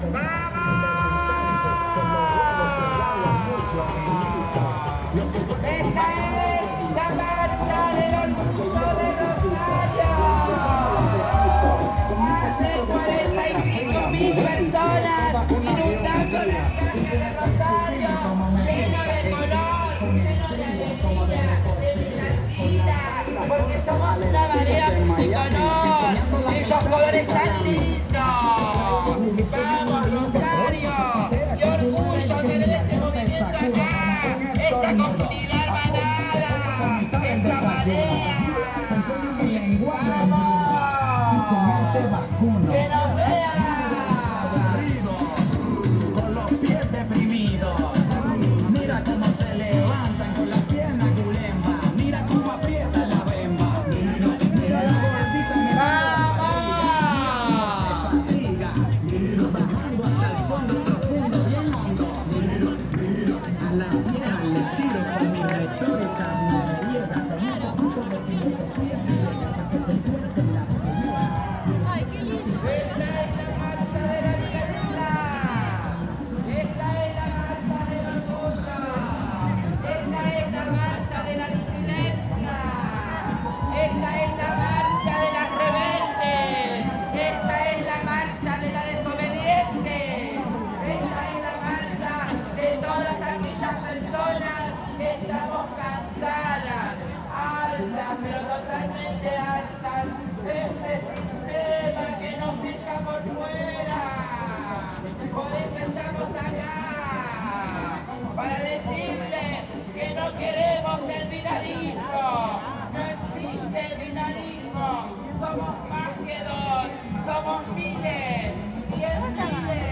psr-marcha-del-orgullo-rosario-2022.mp3